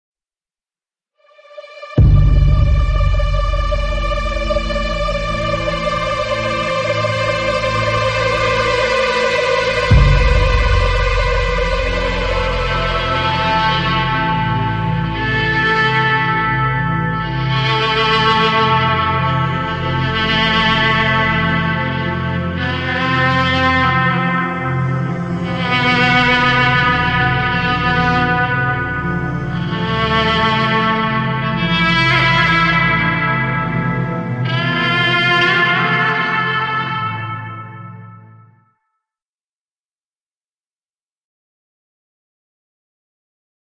Artist: Instrumental
Low Quality